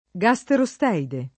vai all'elenco alfabetico delle voci ingrandisci il carattere 100% rimpicciolisci il carattere stampa invia tramite posta elettronica codividi su Facebook gasterosteide [ g a S tero S t $ ide ] o gastrosteide [ g a S tro S t $ ide ] s. m. (zool.)